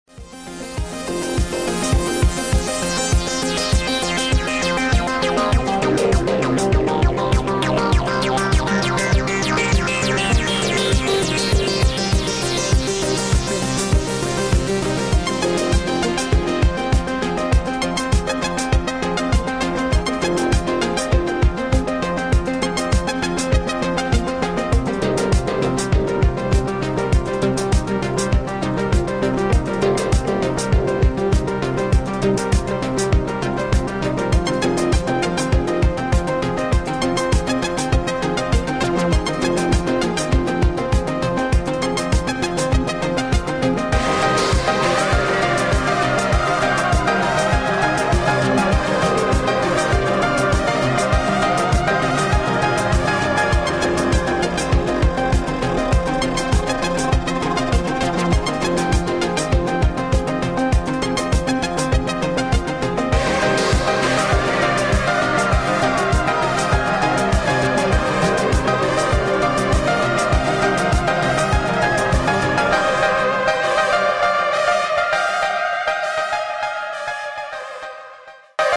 Помогите, пожалуйста, опознать итало-инструментал 80-х.